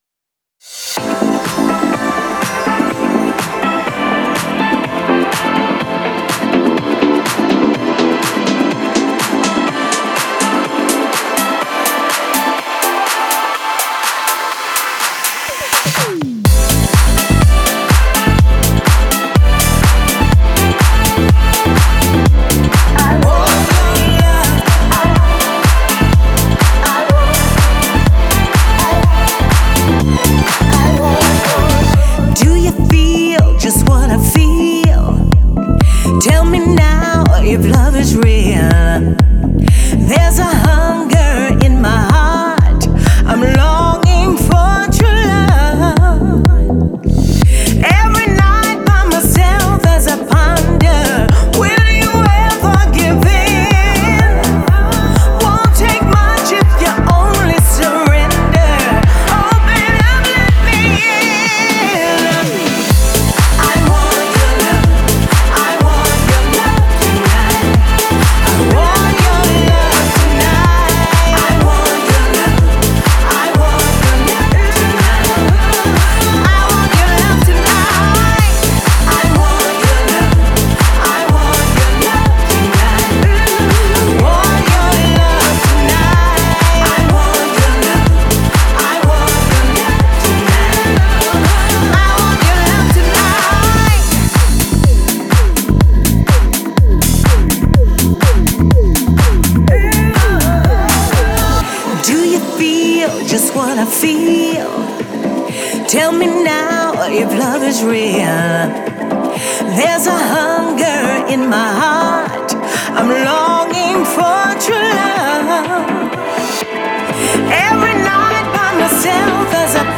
это энергичная танцевальная композиция в жанре хаус